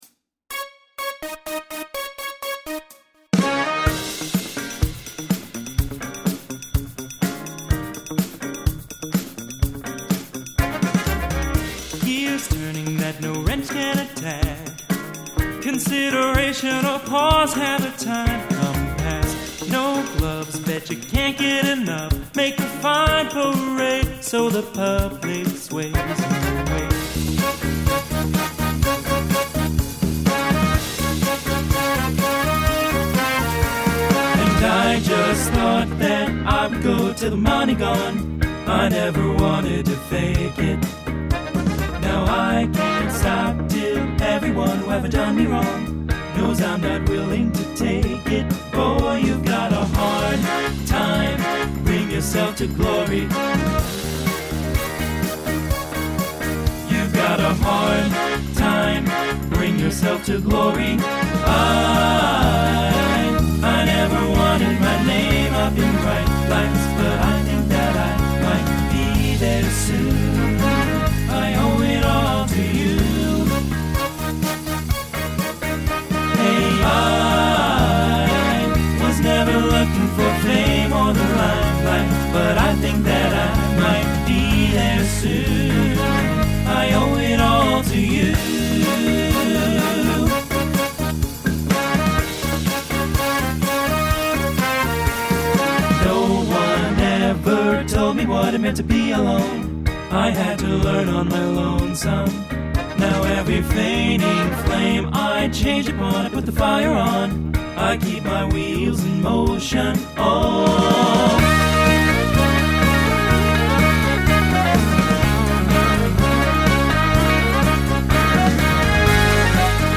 Genre Rock Instrumental combo
Voicing TTB